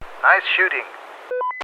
Tag: 战争 语音 战斗喷气机 语音 军事 飞行员 无线电 飞机 飞机 样品 要求 战斗 男性